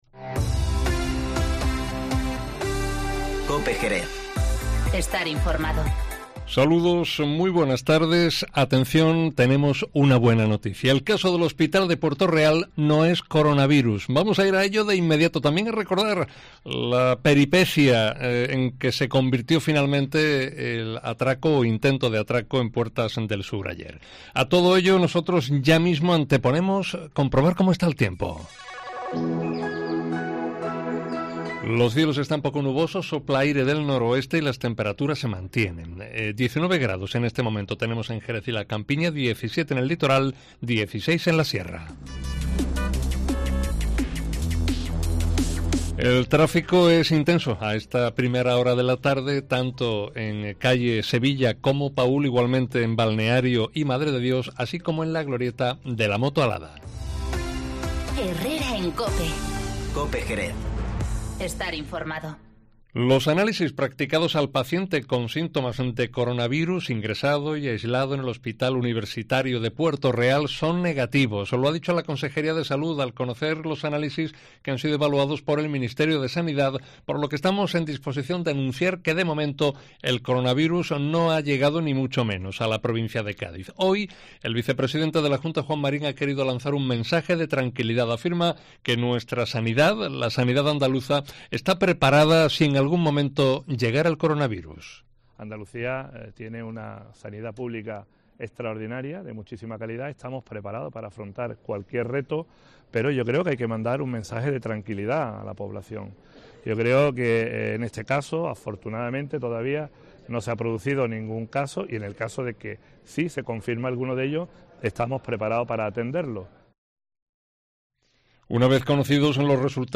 Informativo Mediodía COPE en Jerez 26-02-20